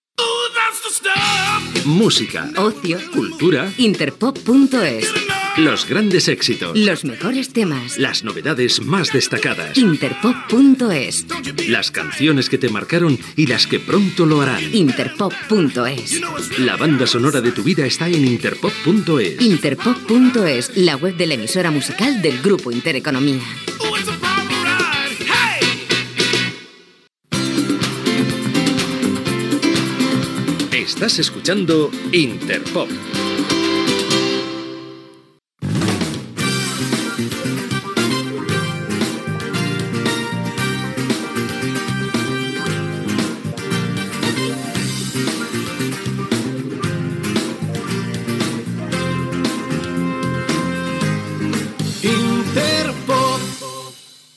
Promoció del web de l 'emissora, identificació i indicatiu.